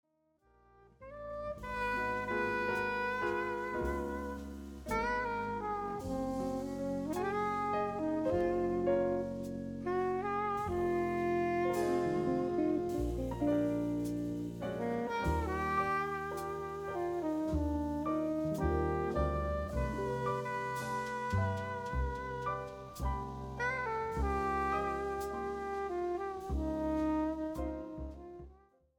and vibrant Latin and Brazilian jazz melodies
Guitar
Piano
Bass
Drums